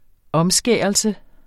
Udtale [ -ˌsgεˀʌlsə ]